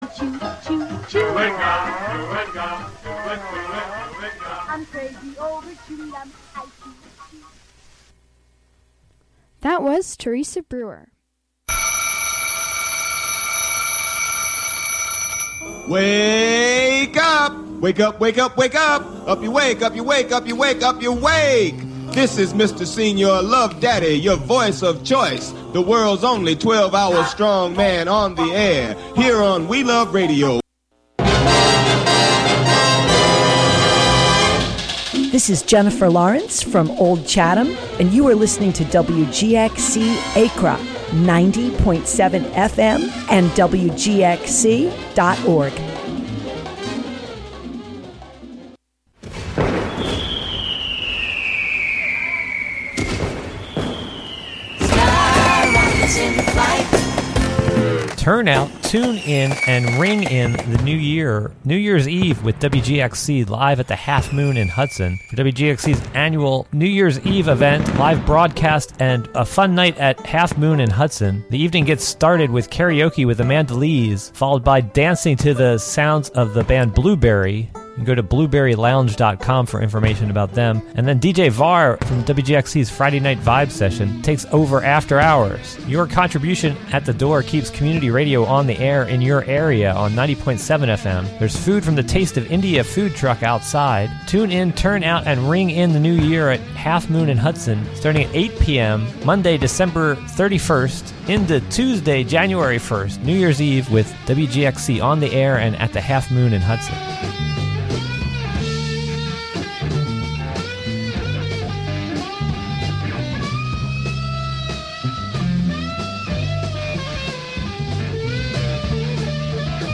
The WGXC Morning Show is a radio magazine show featuring local news, interviews with community leaders and personalities, a rundown of public meetings, local and regional events, with weather updates, and more about and for the community.